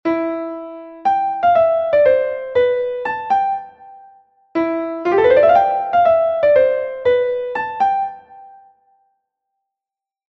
Tirade, die Ausfüllung zweier in der Tonleiter voneinander entfernt liegenden Töne mit stufenweisen Zwischentönen von einerlei Zeitwert, zum Beispiel: